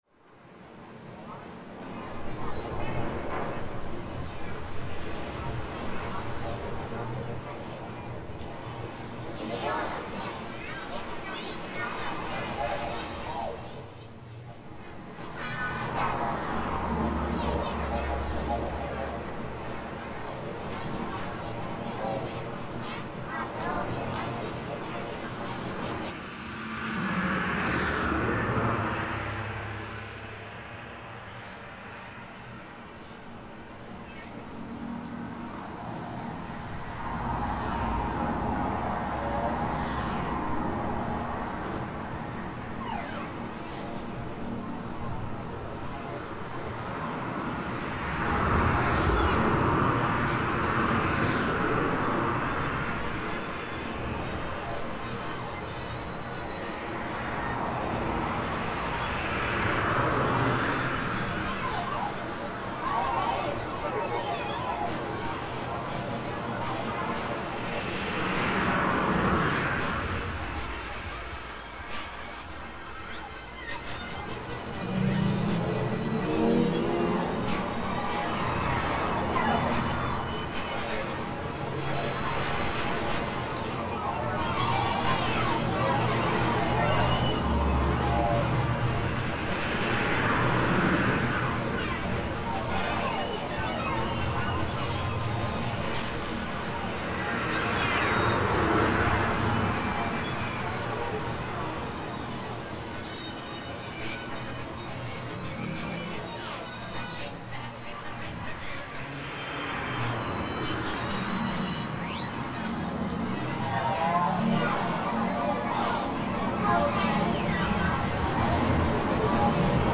Made out of peaks and fragments, uneasy to deploy human considerable claims for emotional continuity.